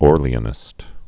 (ôrlē-ə-nĭst)